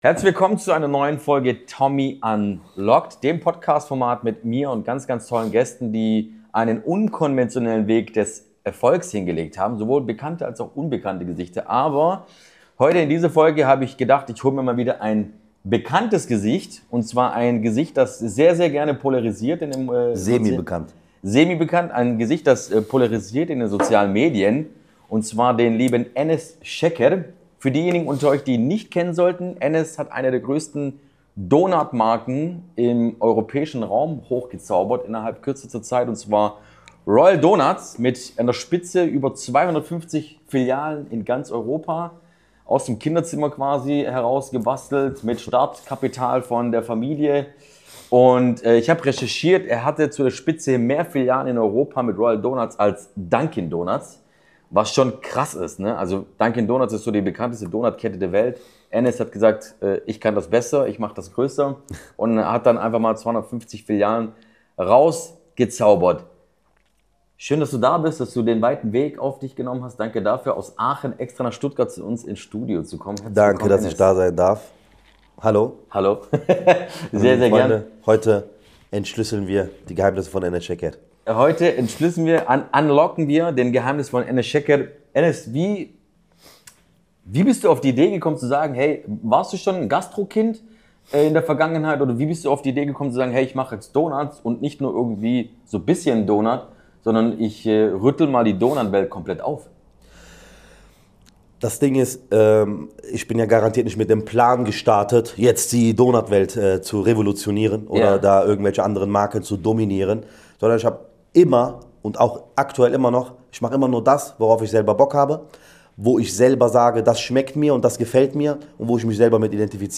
Ein ehrliches Gespräch über Höhen, Tiefen, Verantwortung – und die Frage, was unternehmerischer Erfolg heute wirklich bedeutet.